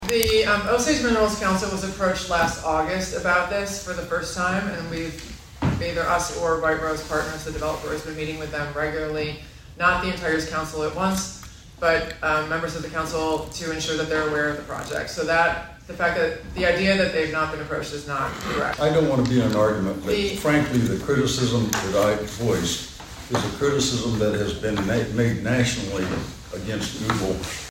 As discussed during Monday's Osage County Commissioners meeting though, there has been some pushback to the development of the data center.